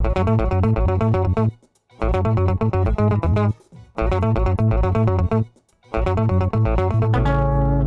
AmajGuitarBass.wav